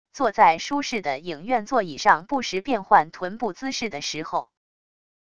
坐在舒适的影院座椅上不时变换臀部姿势的时候wav音频